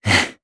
Evan-Vox_Casting1_jp.wav